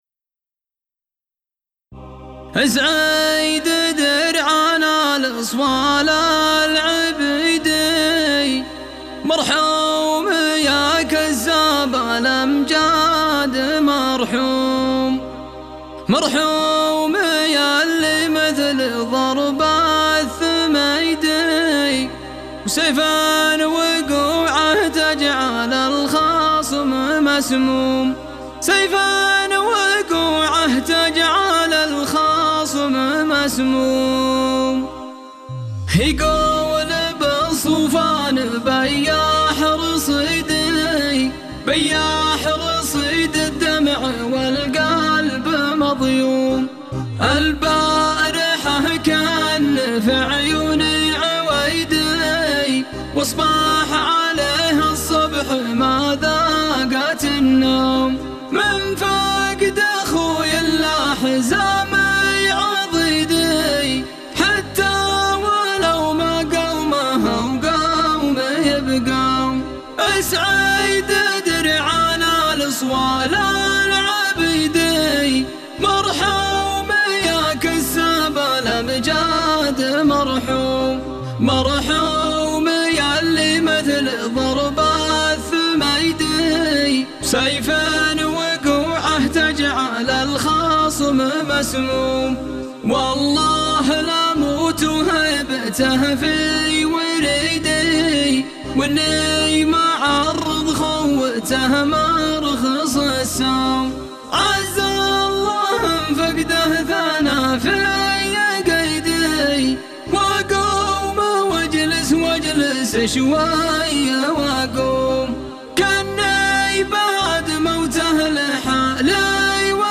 مرثيه مكرر